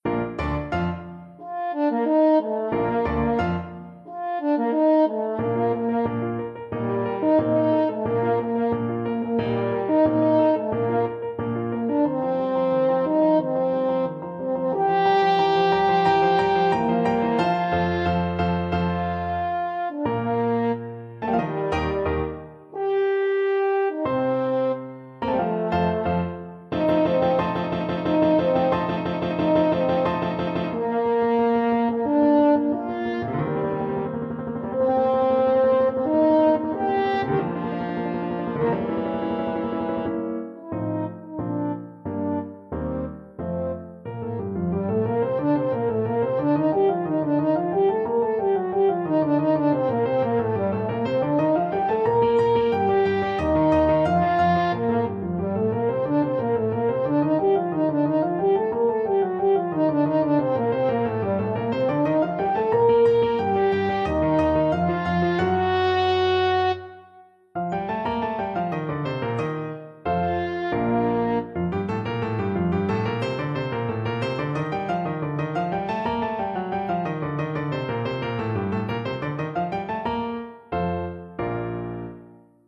Allegre assai = c.90
2/2 (View more 2/2 Music)
E4-Bb5
Classical (View more Classical French Horn Music)